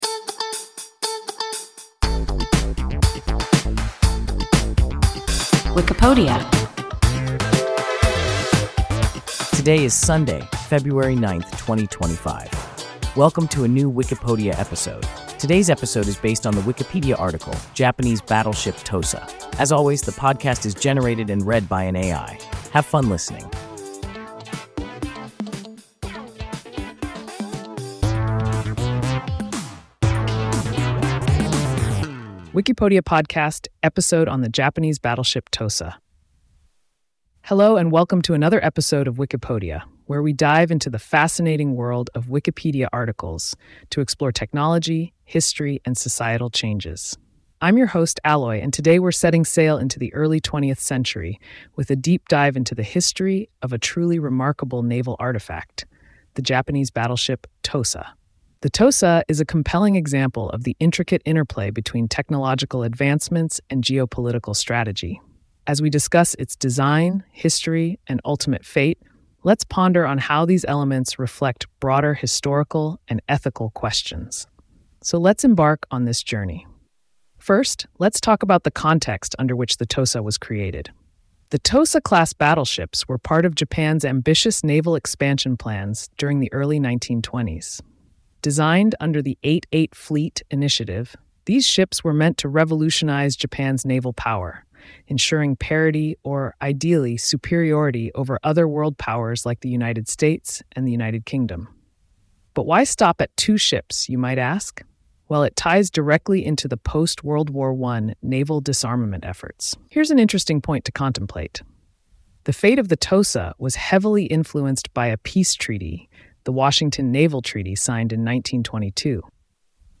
Japanese battleship Tosa – WIKIPODIA – ein KI Podcast